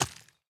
immersive-sounds / sound / footsteps / resources / ore-08.ogg